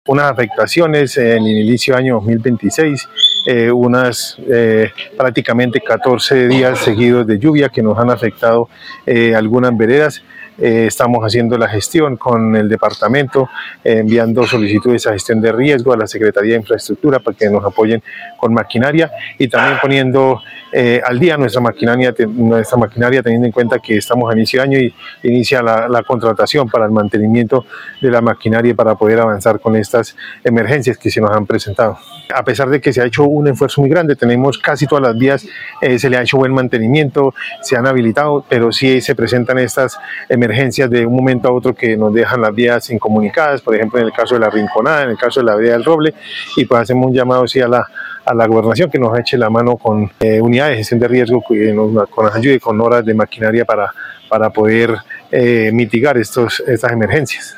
Andelfo Portilla Tarazona, alcalde de Charta